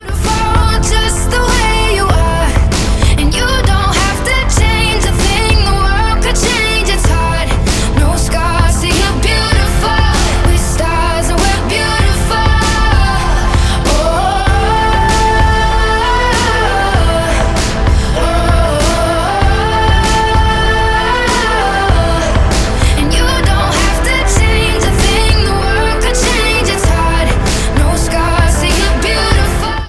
• Pop/R&B